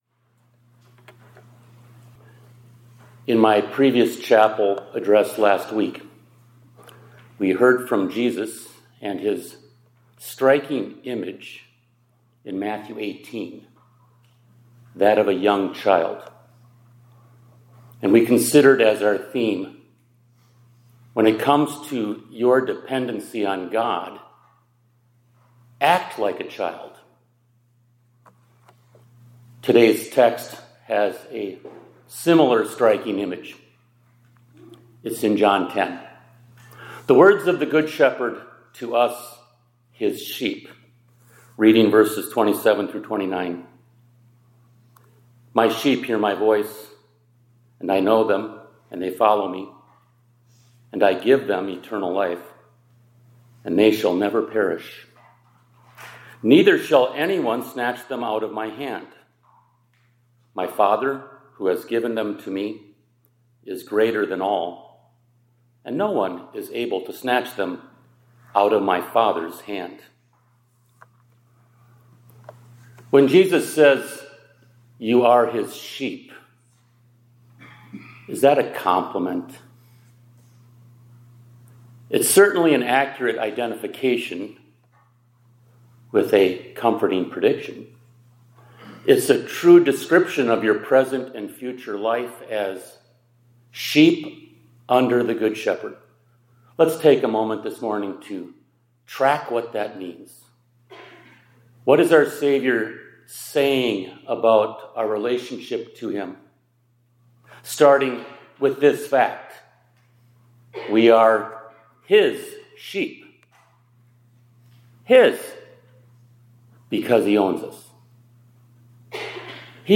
2026-02-03 ILC Chapel — Sheep of the Good Shepherd